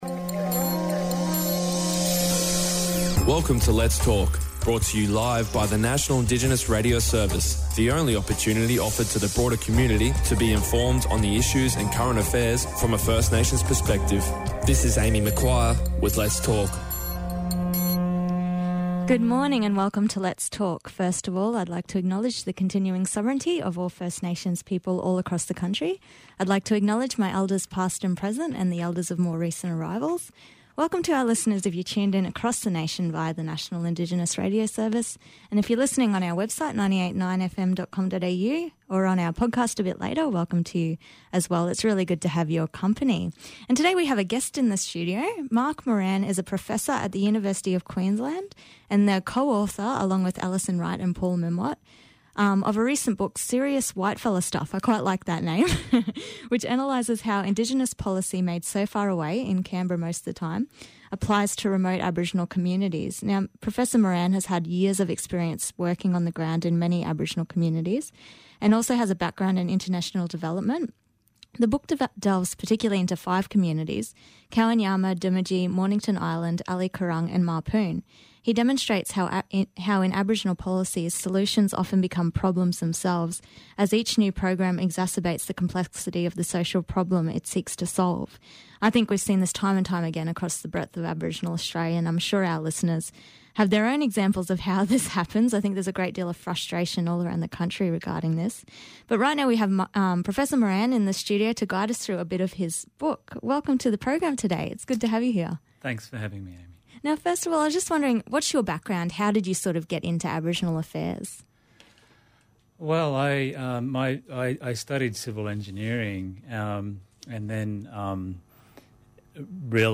And our Triple AAA training students filmed the in-studio discussion.